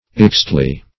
Search Result for " ixtli" : The Collaborative International Dictionary of English v.0.48: Ixtle \Ix"tle\, Ixtli \Ix"tli\([i^]x"tl[-e]), n. 1.